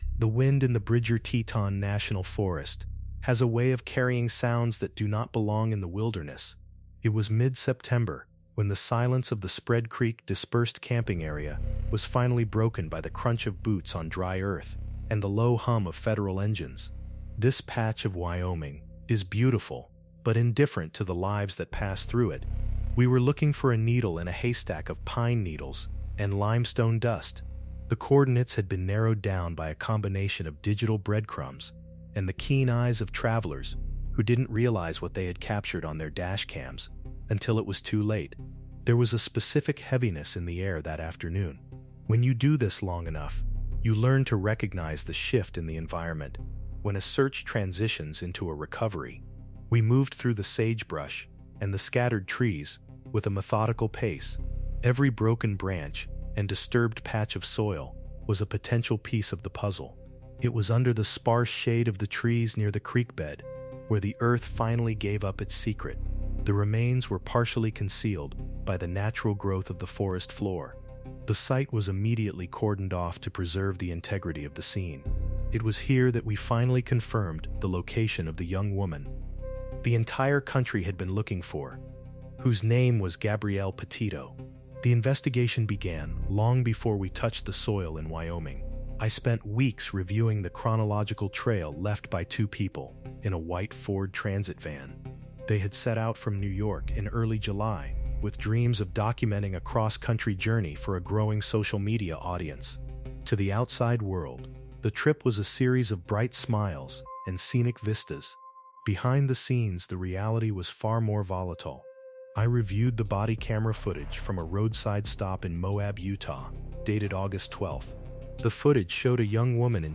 This high-intensity true-crime documentary provides a full forensic investigation into the disappearance and tragic death of Gabby Petito. We go beyond the social media headlines to conduct a detailed chronological reconstruction of the 2021 cross-country journey that captivated the world.